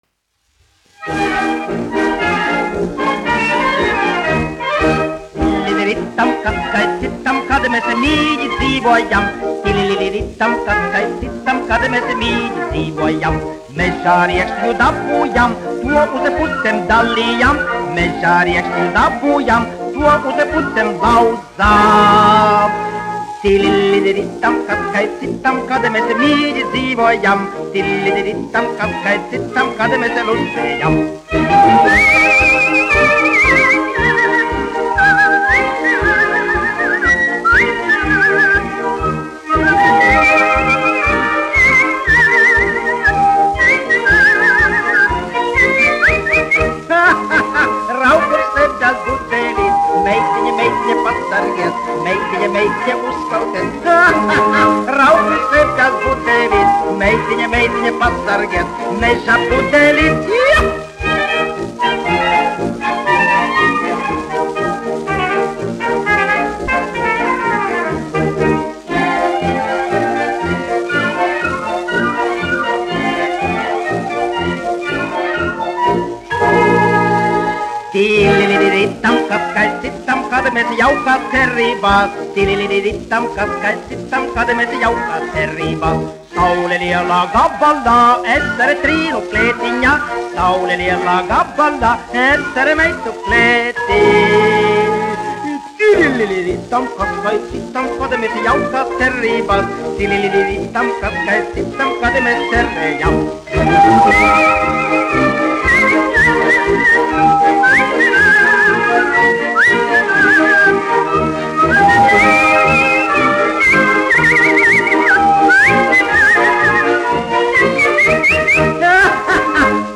1 skpl. : analogs, 78 apgr/min, mono ; 25 cm
Humoristiskās dziesmas
Populārā mūzika -- Latvija
Skaņuplate